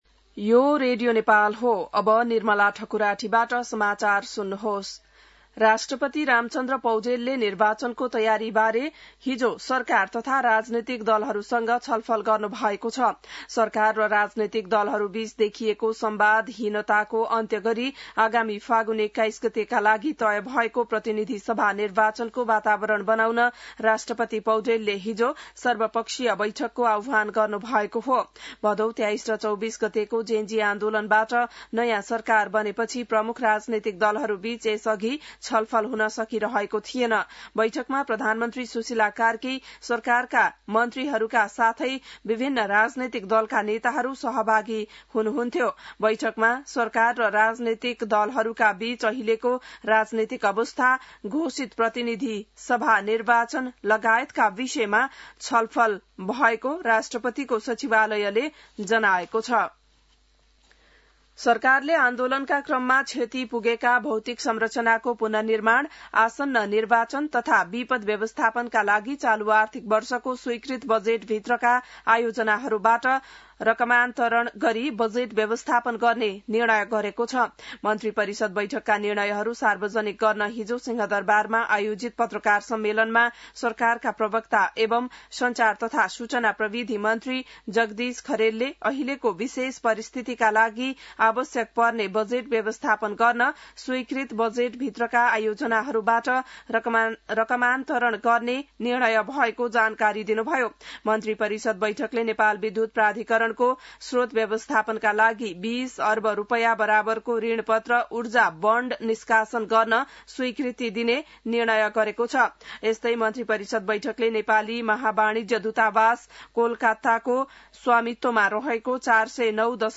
An online outlet of Nepal's national radio broadcaster
बिहान ६ बजेको नेपाली समाचार : २५ असोज , २०८२